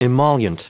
Prononciation du mot emollient en anglais (fichier audio)